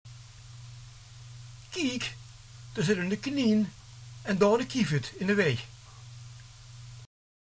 De ij spreek je in Limburg uit als een langgerekte ie (ieë), de ie als een korte ie-klank, als in het Nederlands, en de ei meestal als een ei, eveneens als in het Nederlands.
De ei van “eine” klinkt als in het ABN, de ei van “ei” klinkt bijna als een tweeklank (eihei), het is weer een sleeptoon.
hetlimburgs1.mp3